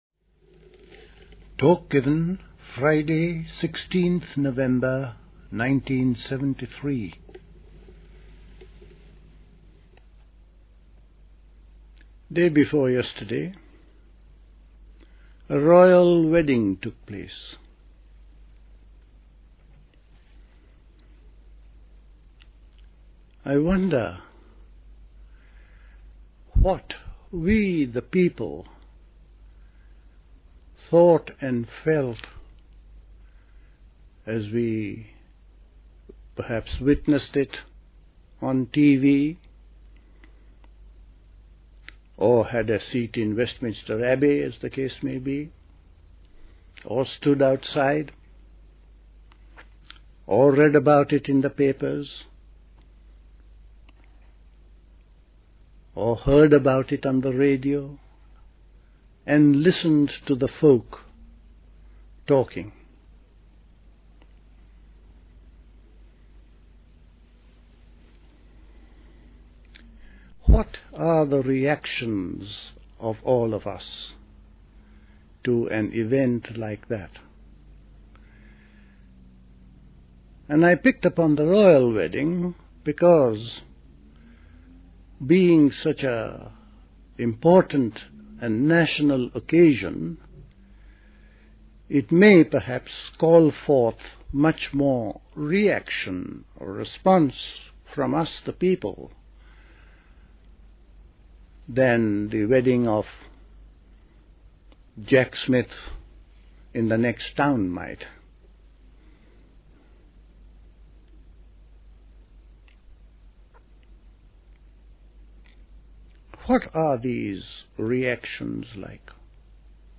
at Dilkusha, Forest Hill, London on 16th November 1973